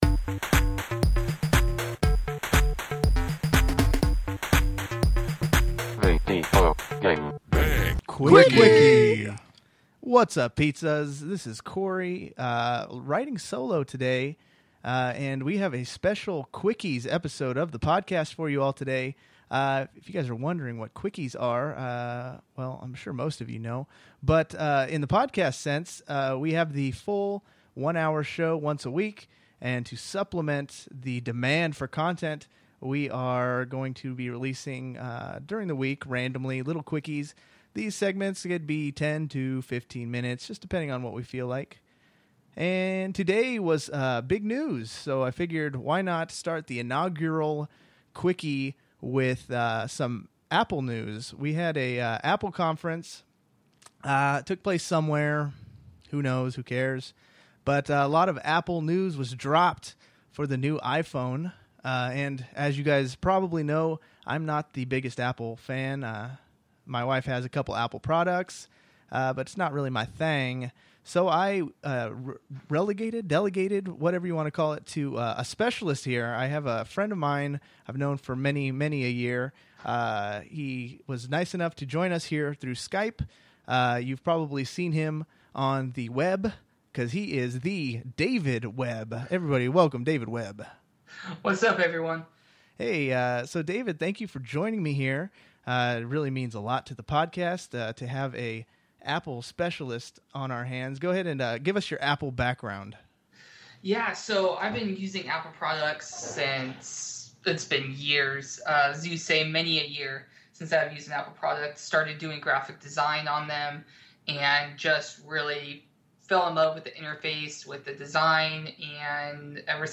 On this special Quickie version of the podcast I talk with an Apple enthusiast